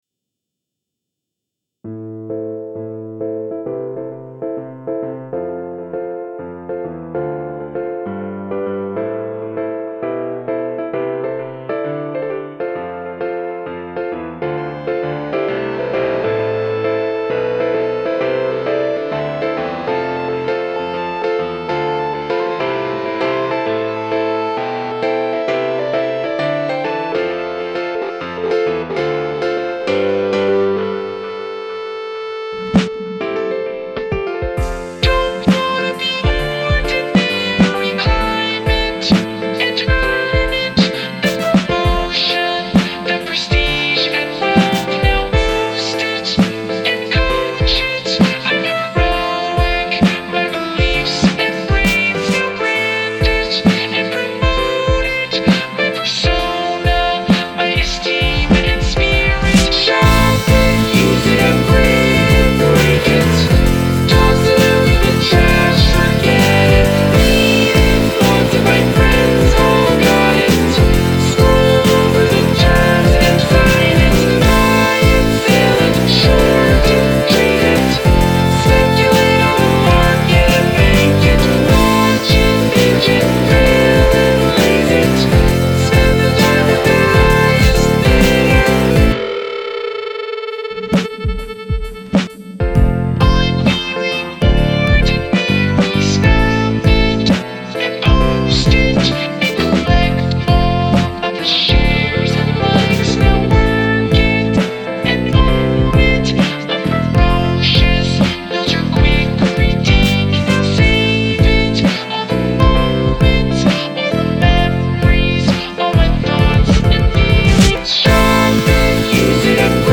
Been working on this new Daft Punk-ish / rockish song... ready for some feedback.
Trying to find the balance between groove and nightmare lol. The robot voice might make the words hard to hear, but I think I'm fine with that as I'll post the lyrics.